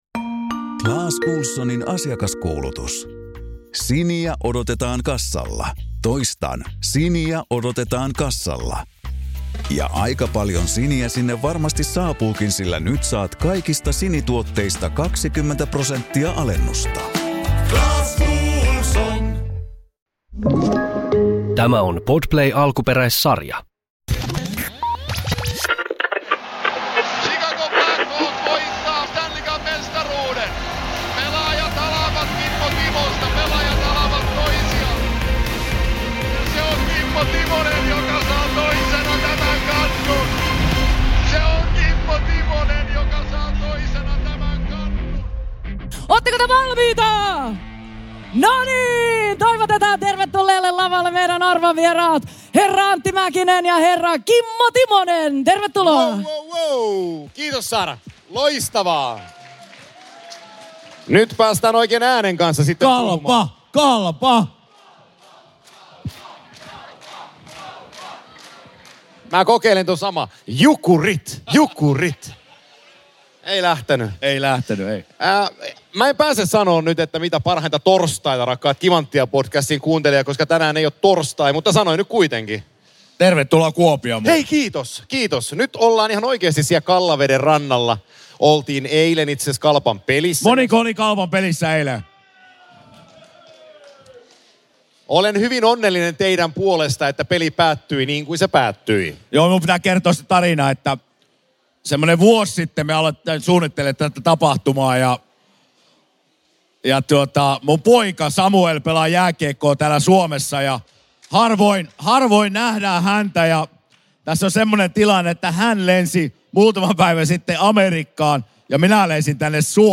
NHL vei sirkuksensa Kuopioon ja Savolaiset ottivat hienosti osaa! NHL Hockey day in Finland-viikonlopussa nauhoitettiin talteen myös Kimanttia-live! Vieraina tässä jaksossa ovat Ossi Väänänen, Esa Tikkanen, Jari Kurri sekä Ville Nieminen.